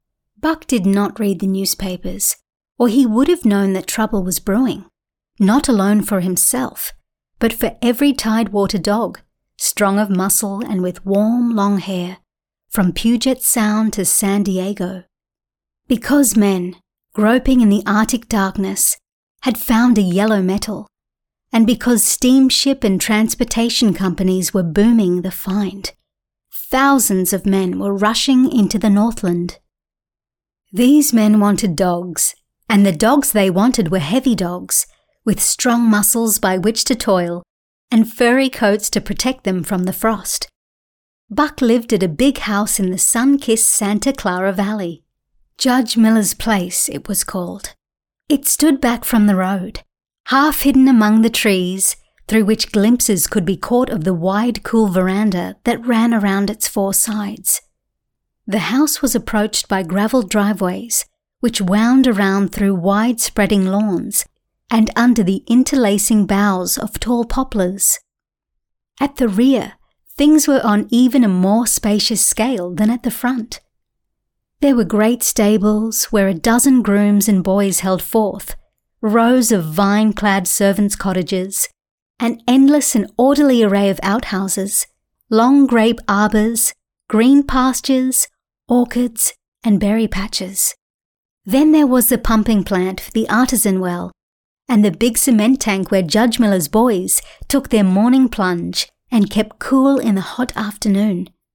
English (Australian)
Female
Audiobooks
FriendlyArticulateCaringFunKindUpbeat